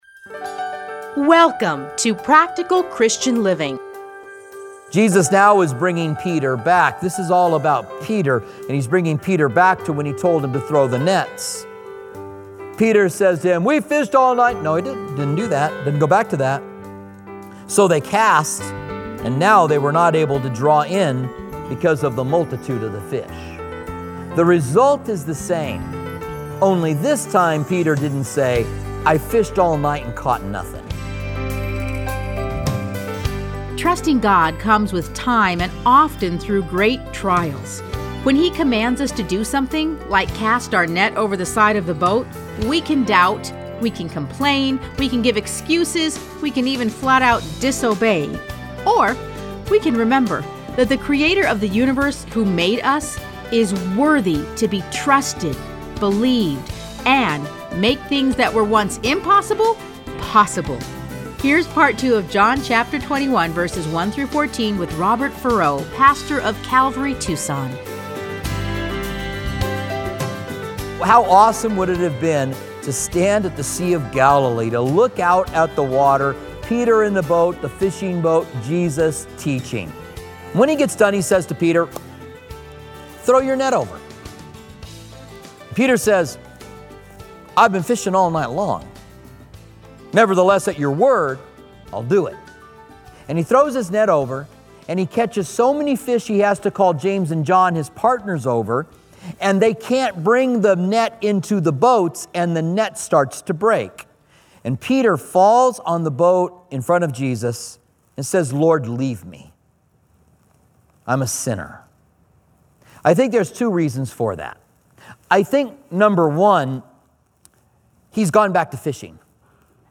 Listen to a teaching from John 21:1-14.